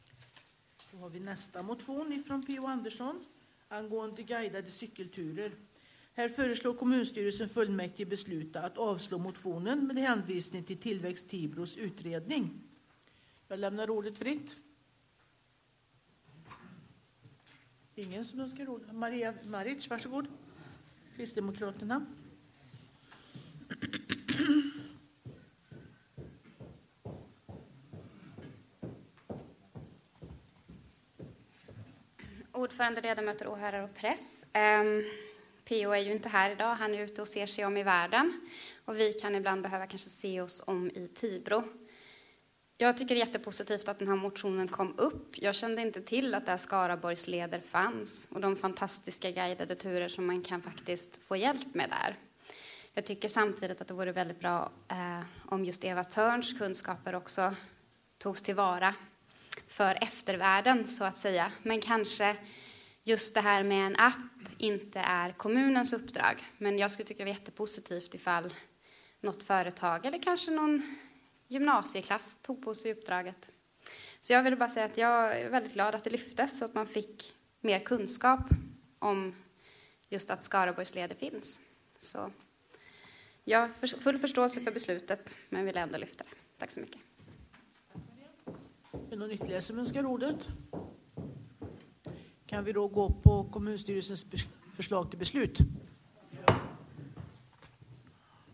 webbsändning från Tibor kommunfullmäktige
Kommunfullmäktige den 14 december 2015 kl. 18.00.